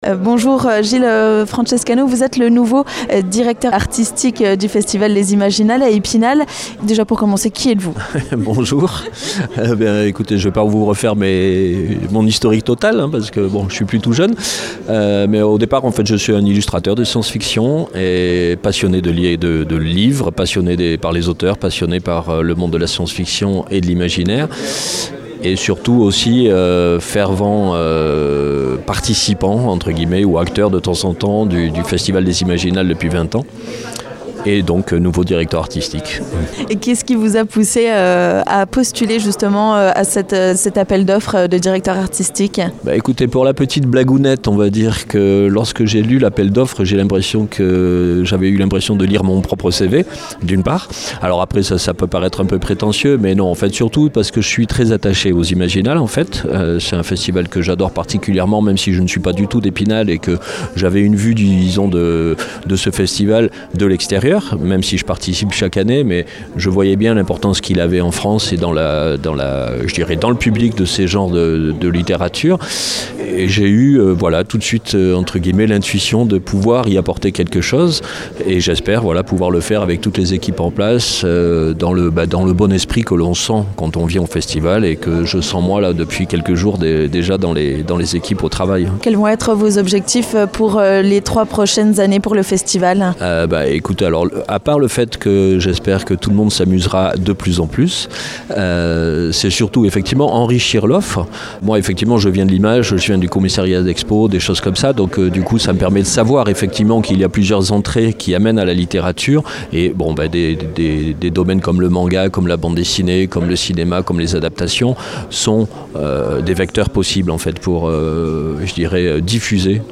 A cette occasion, Vosges FM est allé à sa rencontre pour découvrir qui il était et connaître ses objectifs pour les Imaginales.